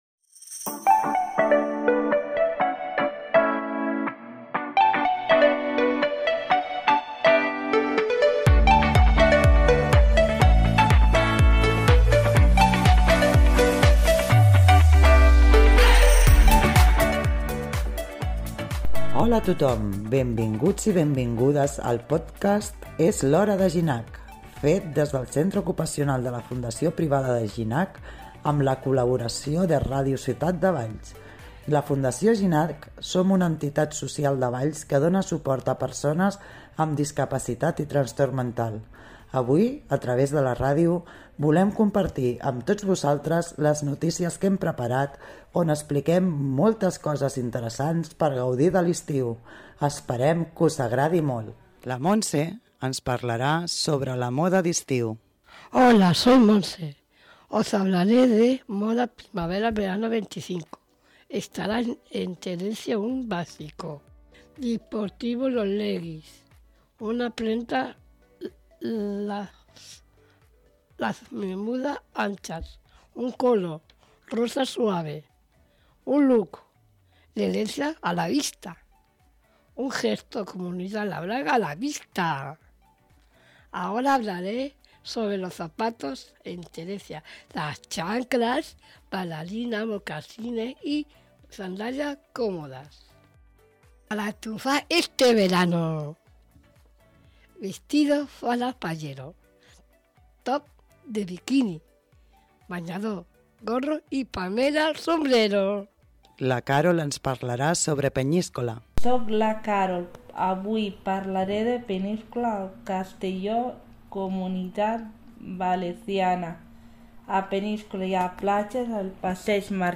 Benvinguts i benvingudes a És l’Hora de Ginac, un pòdcast fet des del centre ocupacional de la Fundació Ginac i que compta amb la col·laboració de Ràdio Ciutat de Valls.